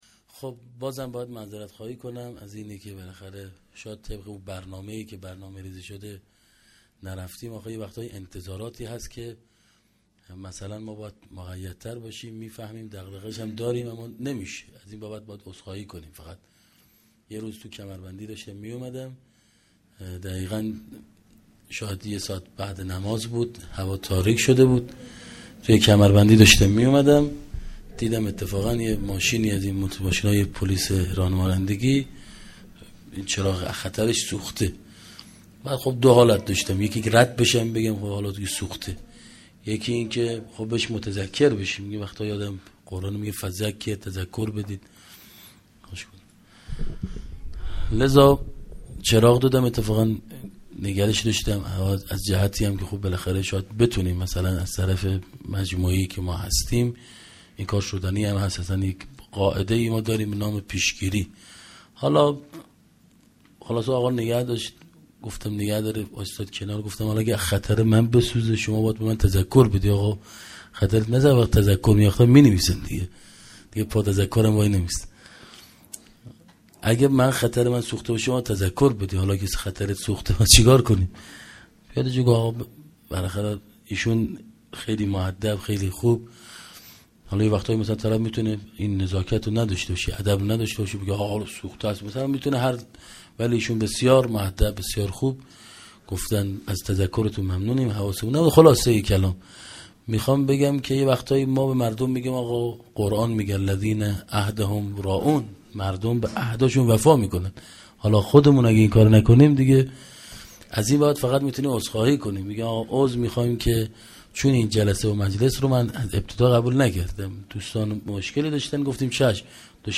sokhanrani.mp3